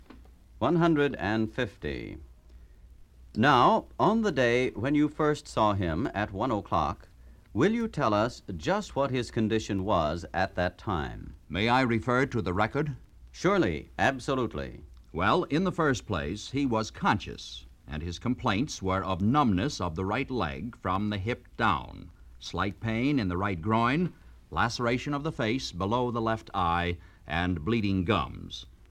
To my surprise, I discovered nearly 70 LPs, each holding a treasure trove of more than 400 dictations in various speeds and topics, including material written in Gregg shorthand.
150 Testimony
It took us several months to carefully extract the dictations from the LPs, convert them into a digital format, and upload them into the NCRA Learning Center.
150-testimony-medical.mp3